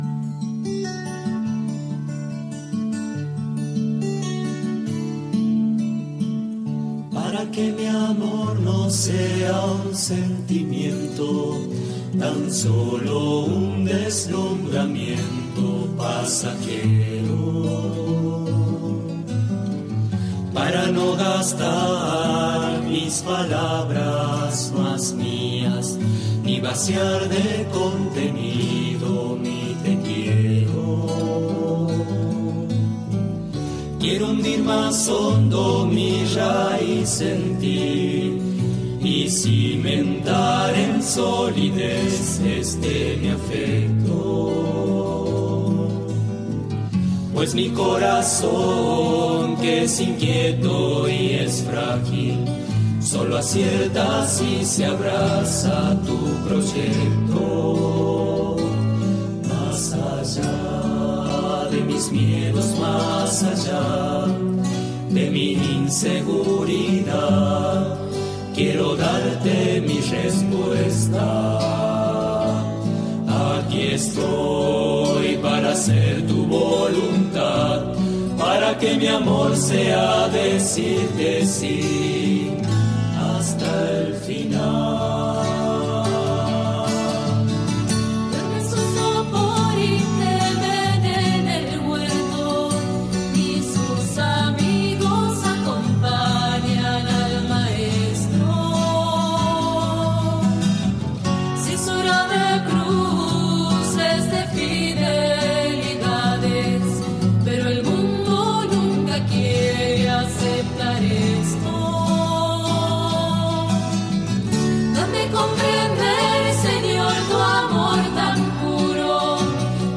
12/12/2024 – En el día de la Virgen de Guadalupe compartimos la catequesi del día